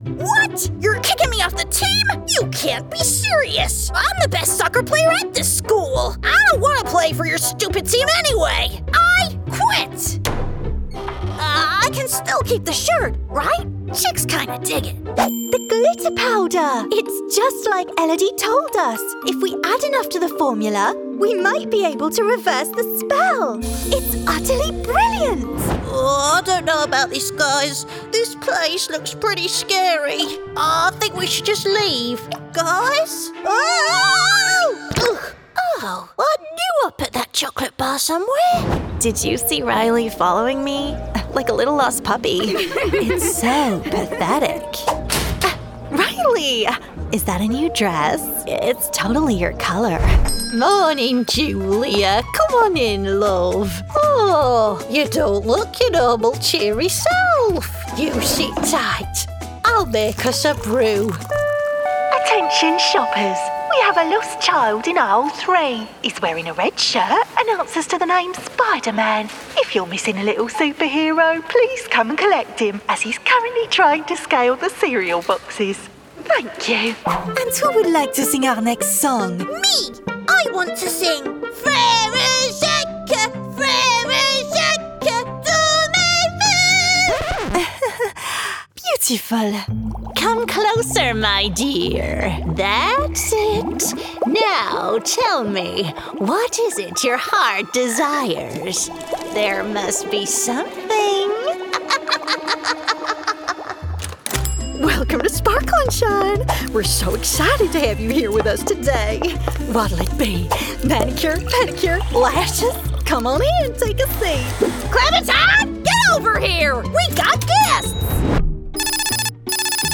Female
British English (Native)
Bright, Friendly, Versatile, Character, Confident, Corporate, Engaging, Natural, Reassuring, Warm
My natural voice is bright, upbeat and friendly with a neutral British accent.
Home Studio Sample.mp3
Microphone: Rode NT1-A
Audio equipment: Audient iD4 interface, treated studio booth